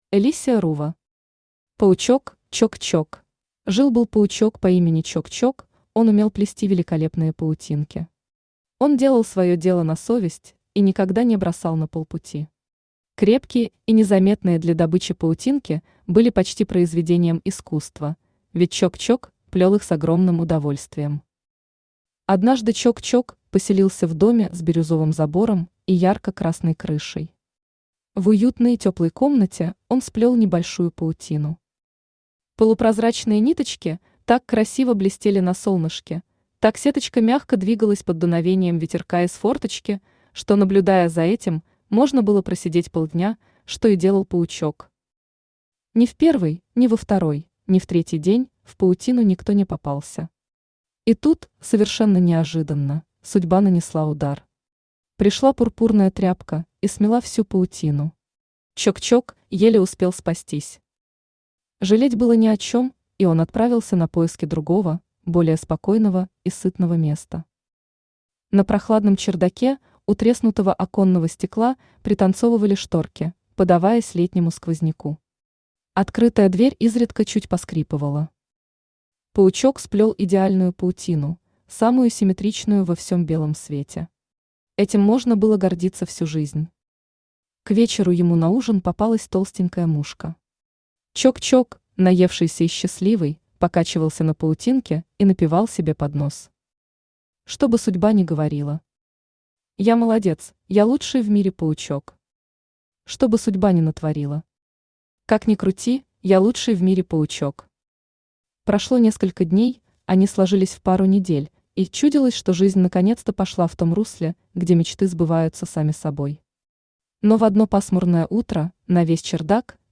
Aудиокнига Паучок Чок-Чок Автор Alicia Ruva Читает аудиокнигу Авточтец ЛитРес.